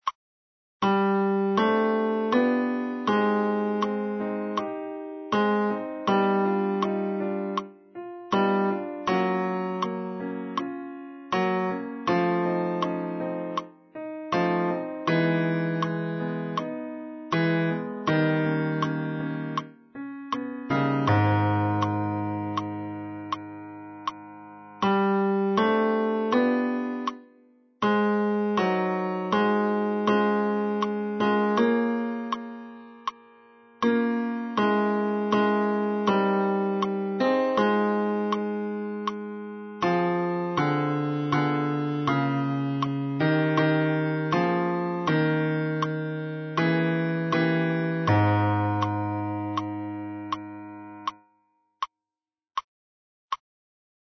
Bonjour – bass